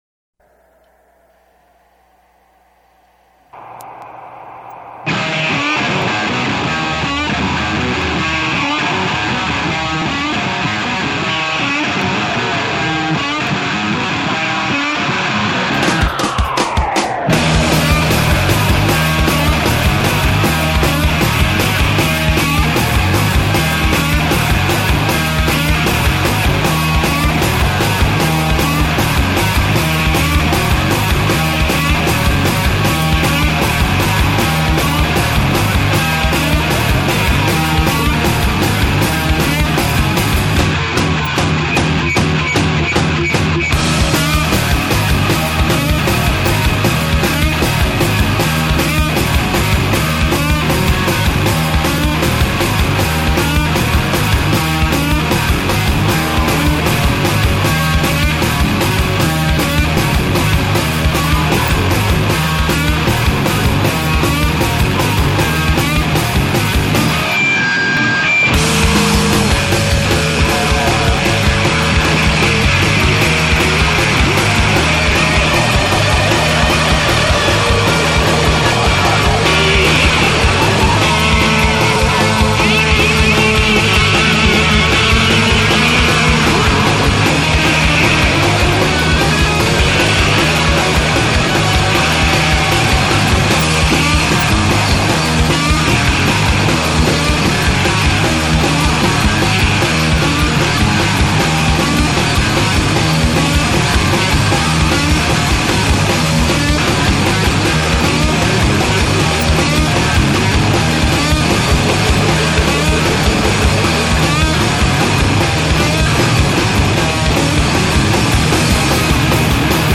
Motz egin jata elkarrizketa, uhinak legez hegaz.